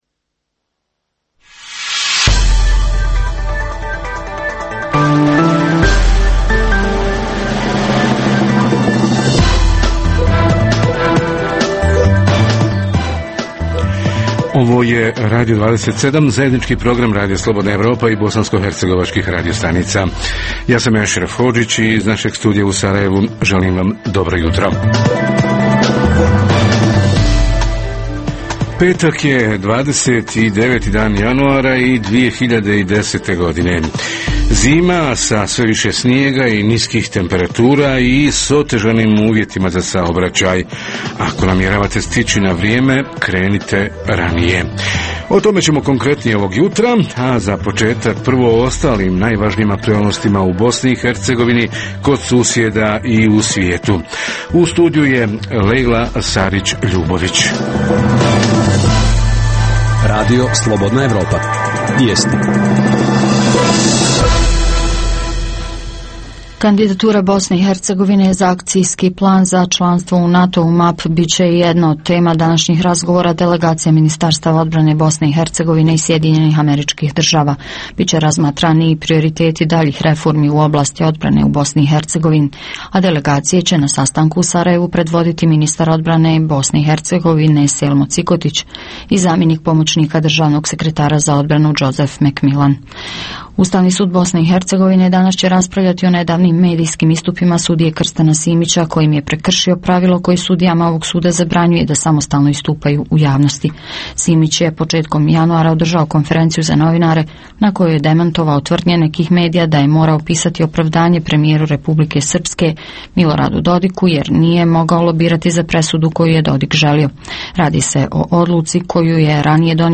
Socijalna zaštita otpuštenih s posla – koja su njihova prava i kako se ostvaruju Reporteri iz cijele BiH javljaju o najaktuelnijim događajima u njihovim sredinama.
Redovni sadržaji jutarnjeg programa za BiH su i vijesti i muzika.